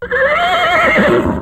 Horse
Horse.wav